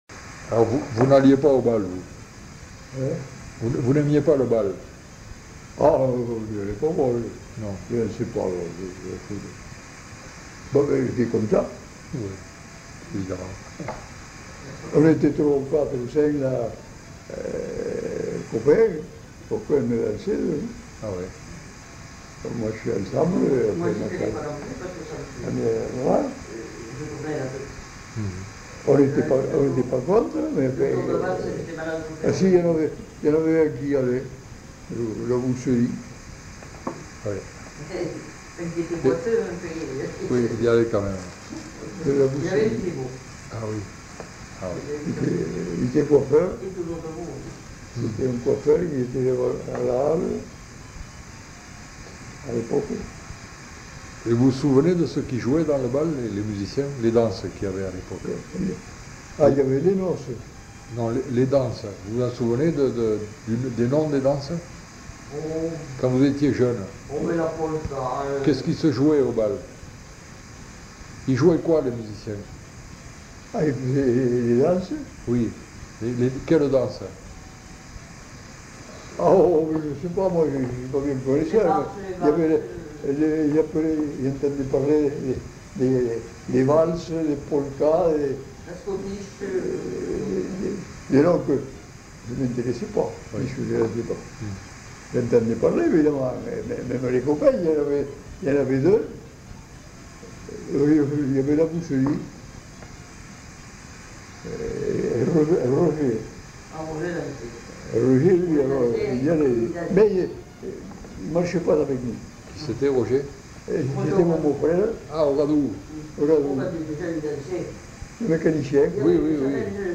Aire culturelle : Haut-Agenais
Genre : témoignage thématique
On entend un enregistrement d'accordéon en fond.